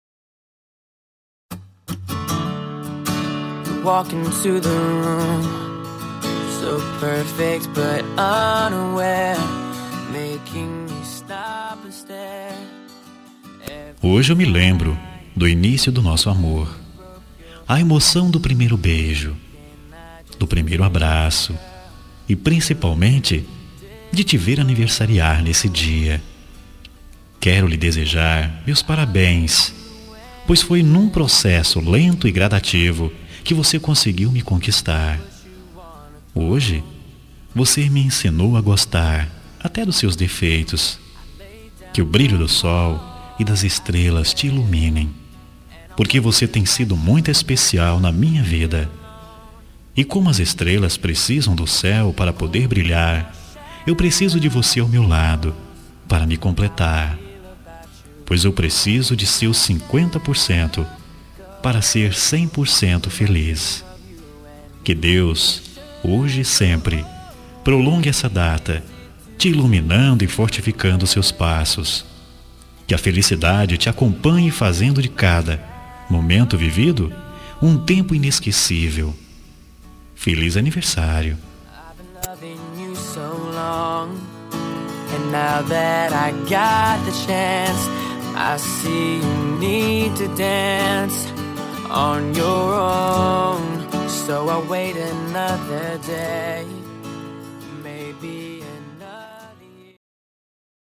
Telemensagem de Aniversário Romântico – Voz Masculina – Cód: 1056